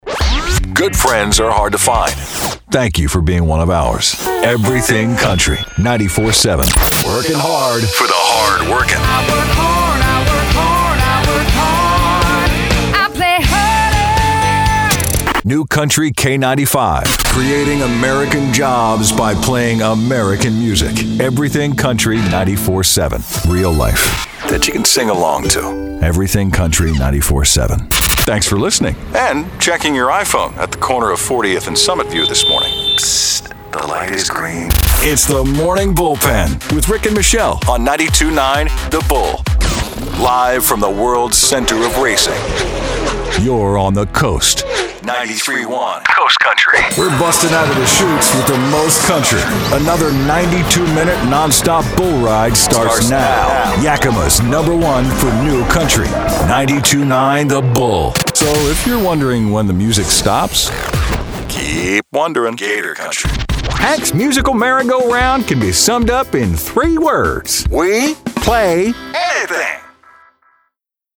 Rugged. Warm
Country Radio Imaging
Middle Aged